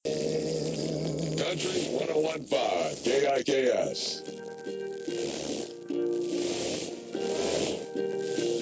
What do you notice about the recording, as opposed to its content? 101.5 KIKS. He picked up the signal on an ELAD Software-Defined Radio (SDR) receiver with a small 20′ Yagi antenna. At 11:09 p.m., a clearer identification came and he heard, “Country 101-5 KIKS.”